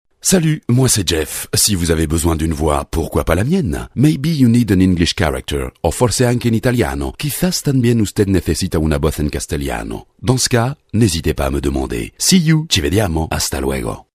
Voix masculine
Voix Graves